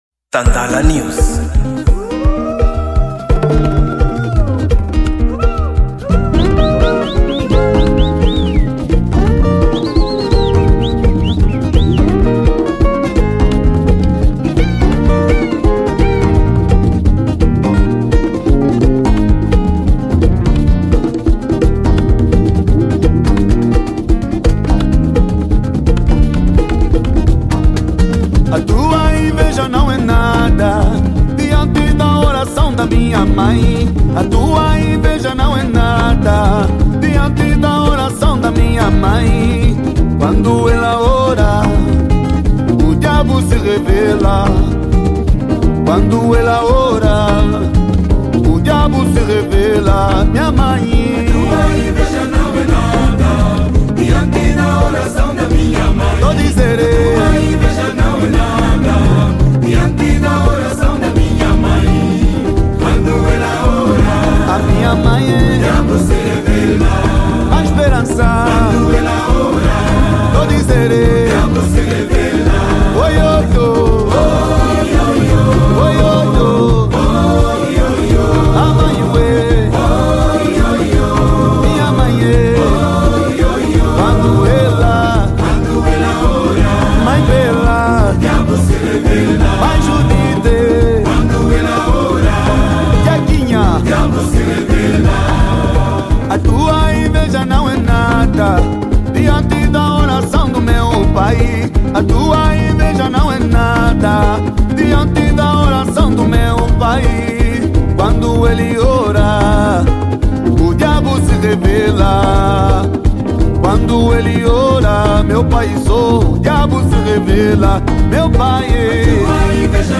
Gênero: Gospel